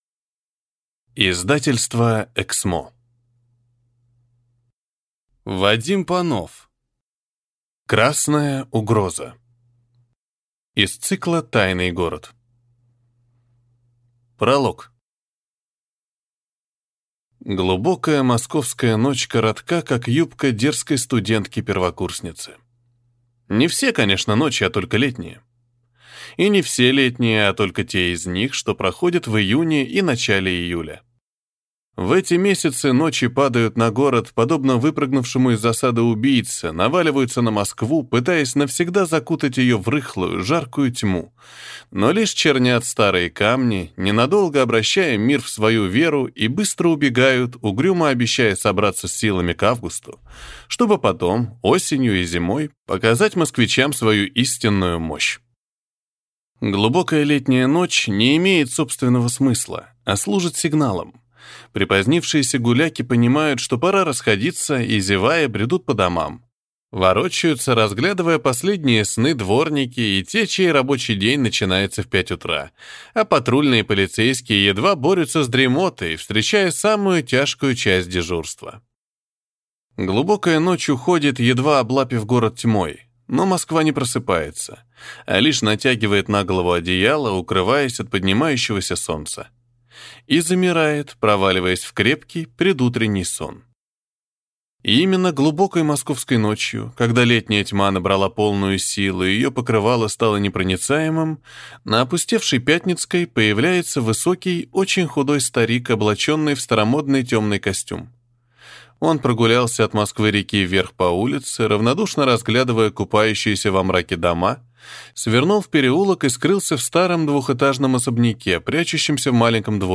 Аудиокнига Красная угроза | Библиотека аудиокниг